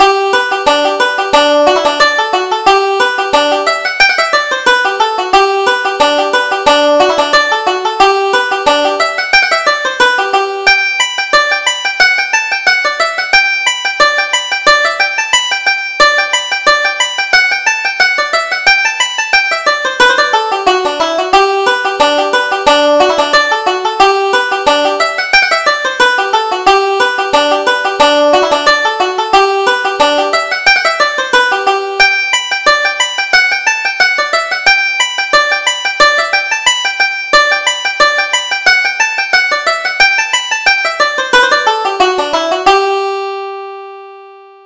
Reel: The Sligo Lasses